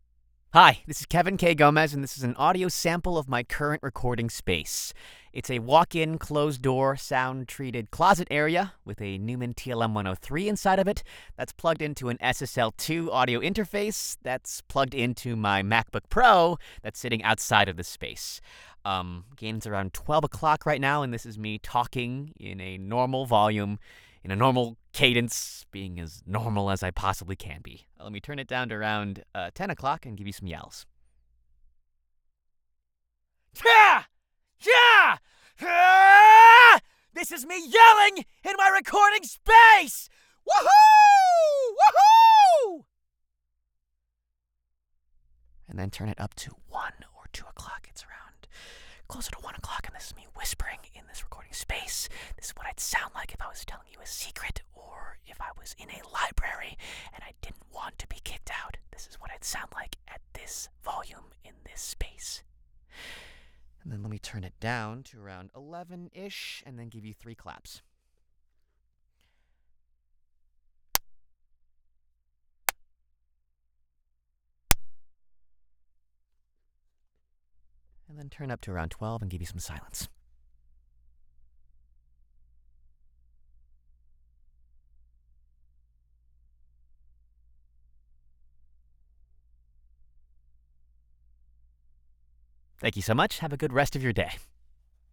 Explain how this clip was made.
Home Studio Spec • Neumann TLM 103 XLR Microphone • SSL2 Audio Interface